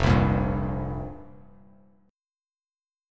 Fsus2sus4 chord